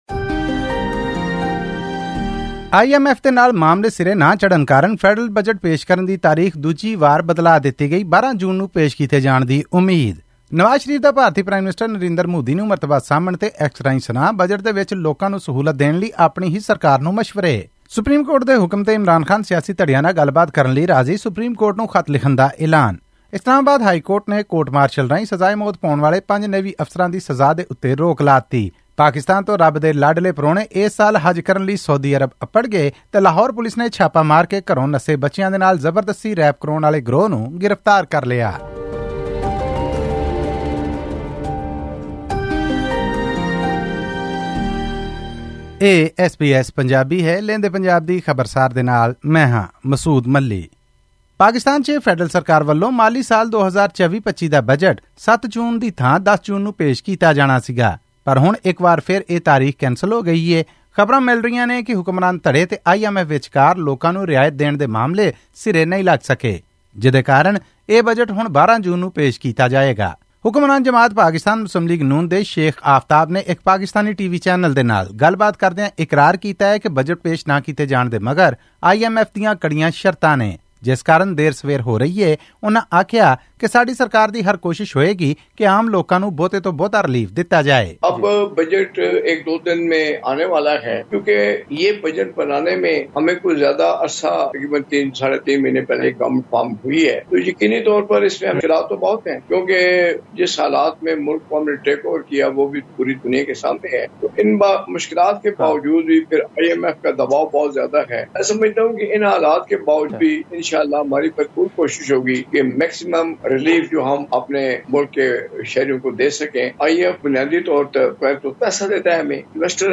ਹੋਰ ਵੇਰਵੇ ਲਈ ਸੁਣੋ ਇਹ ਆਡੀਓ ਰਿਪੋਰਟ...